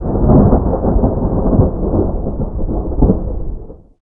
thunder38.ogg